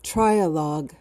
PRONUNCIATION: (TRY-uh-log) MEANING: noun: A discussion in which three parties participate.